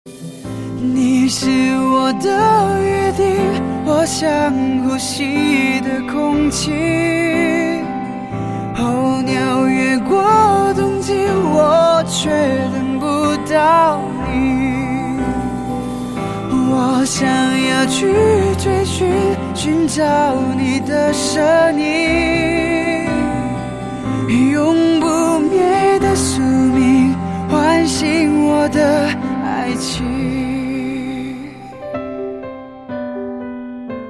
M4R铃声, MP3铃声, 华语歌曲 94 首发日期：2018-05-15 12:25 星期二